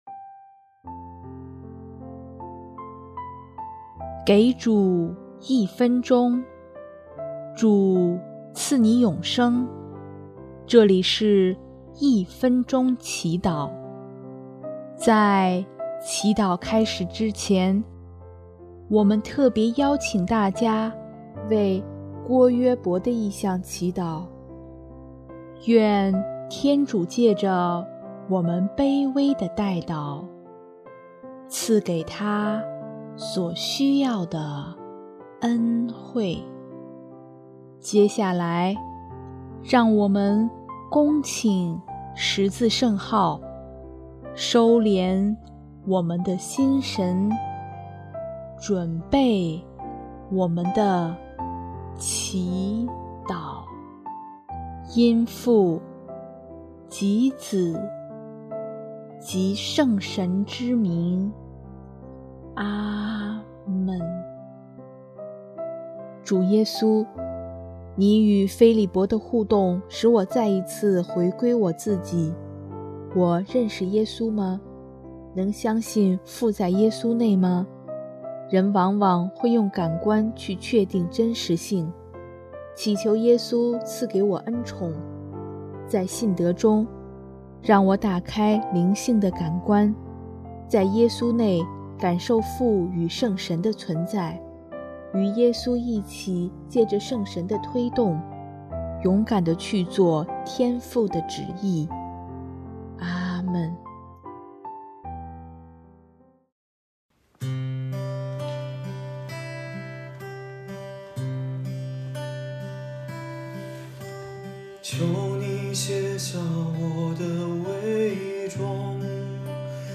音乐： 第四届华语圣歌大赛参赛歌曲《治愈》